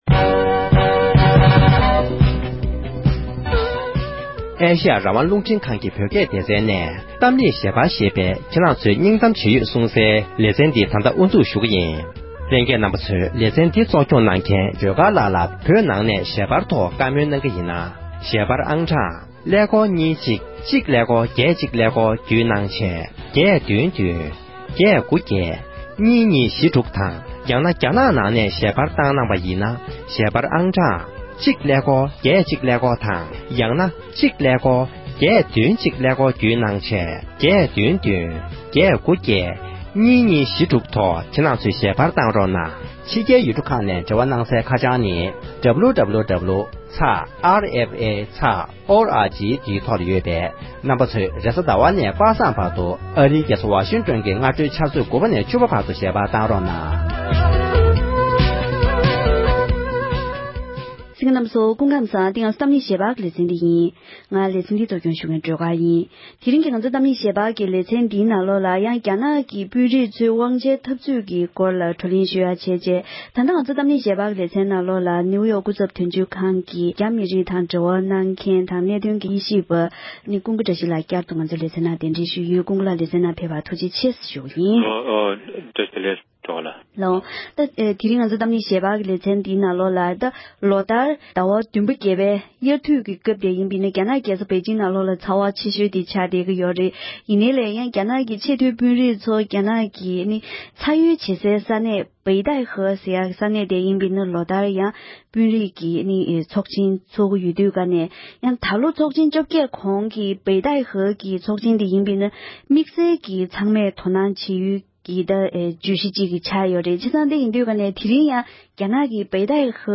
འདི་ལོ་སྟོན་དུས་རྒྱ་ནག་ཆབ་སྲིད་ལྷན་ཁང་གི་རྒྱུན་ལས་ལ་འགྱུར་བ་འགྲོ་གི་ཡོད་དུས་བོད་ལ་ཤུགས་རྐྱེན་ཇི་འདྲ་ཡོང་མིན་ཐོག་བགྲོ་གླེང་།